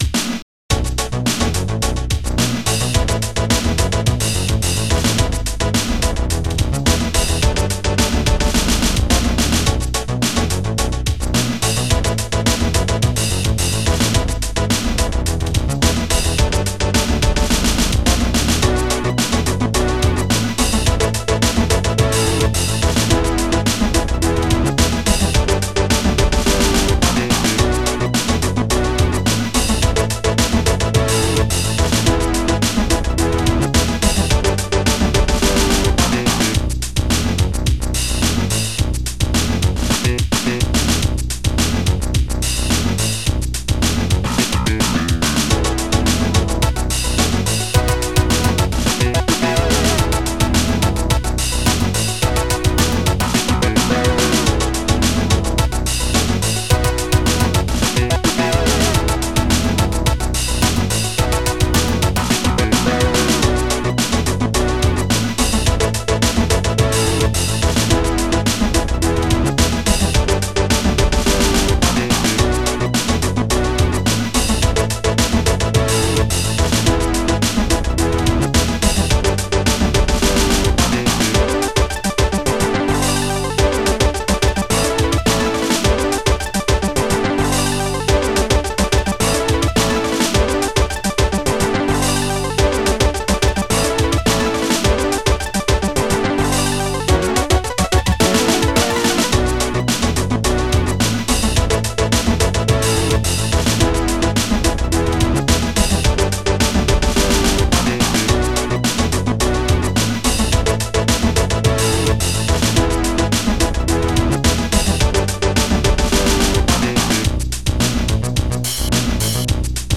ST-20:funkdrum1
ST-10:RealBass